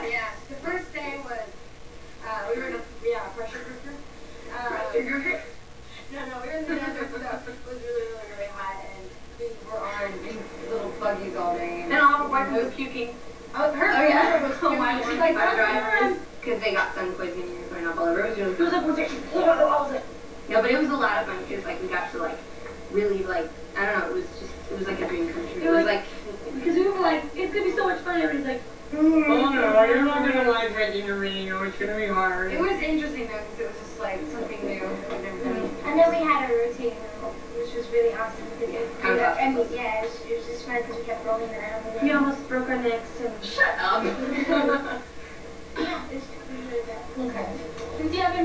Interview: Dream.wav